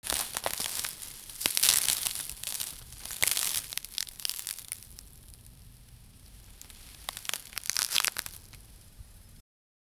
Clean recording with no background noise. 0:01 Crackling leaves and crumbling soil 0:10 Crashing Into Car 0:10
crackling-leaves-and-crum-tlwtqead.wav